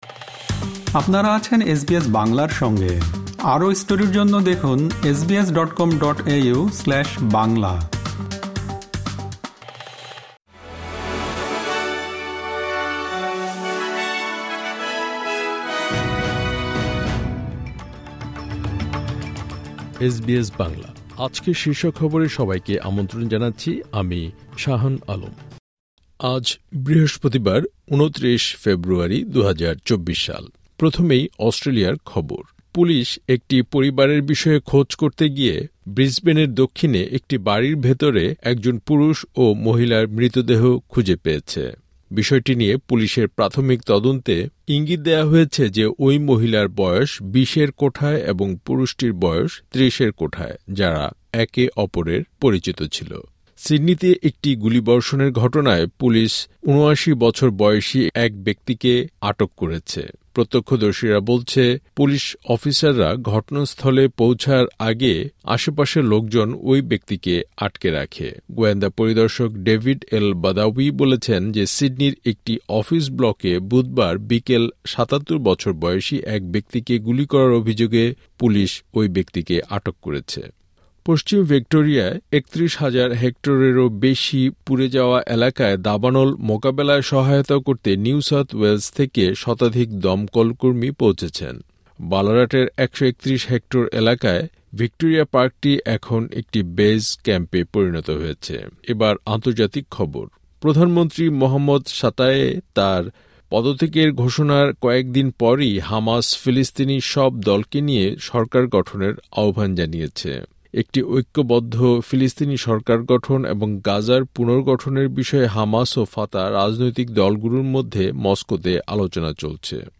এসবিএস বাংলা শীর্ষ খবর: ২৯ ফেব্রুয়ারি, ২০২৪